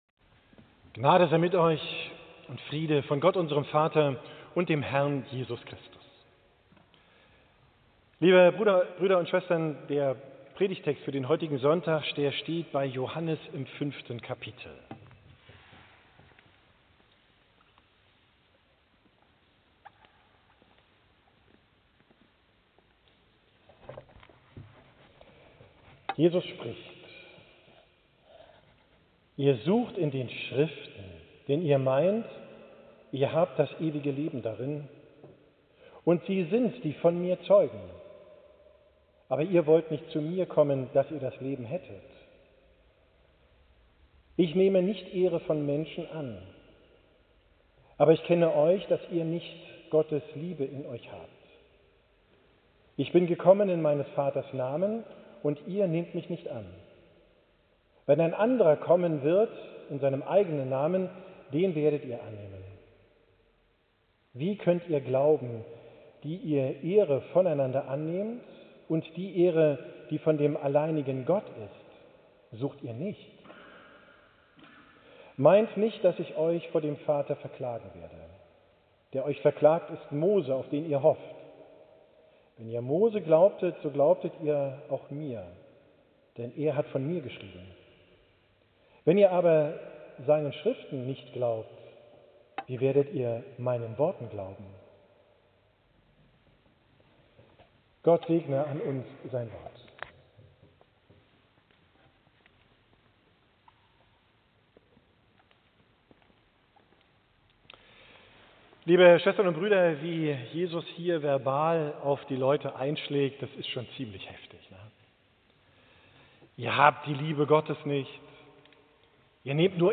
Predigt vom 1.